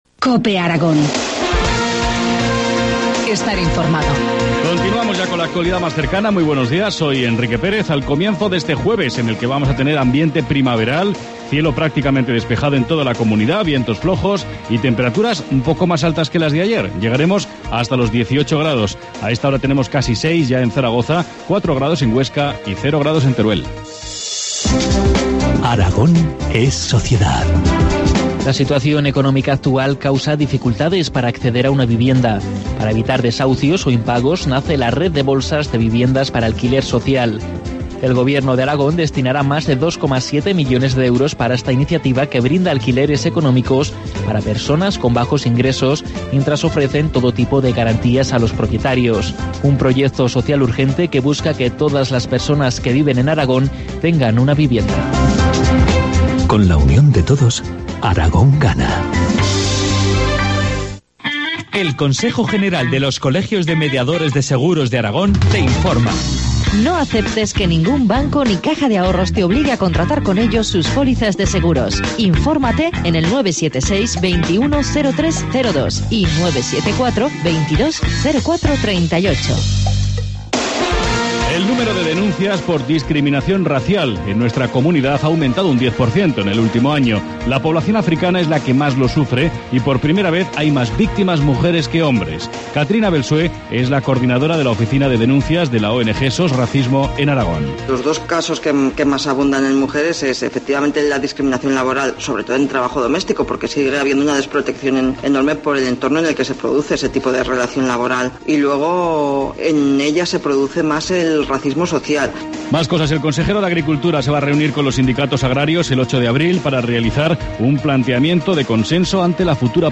Informativo matinal, jueves 21 de marzo, 8.25 horas